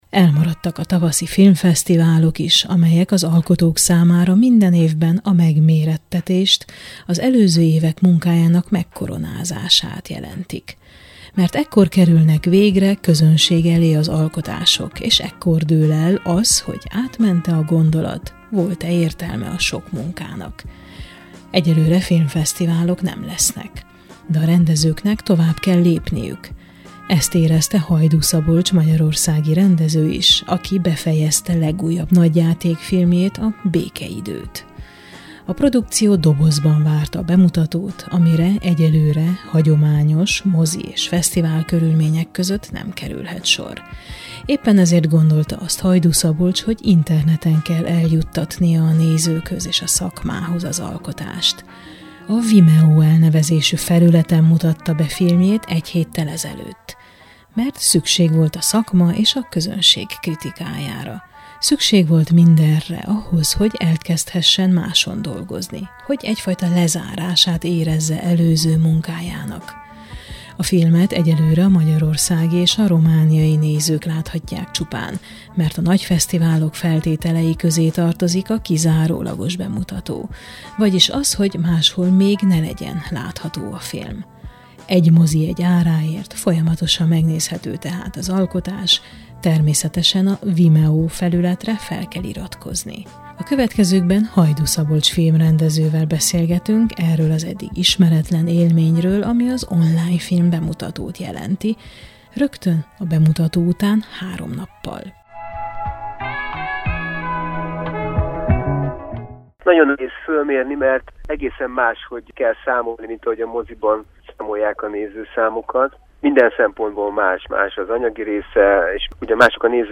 Beszélgetés a Békeidő című filmről Hajdú Szabolcs rendezővel és a csapat néhány tagjával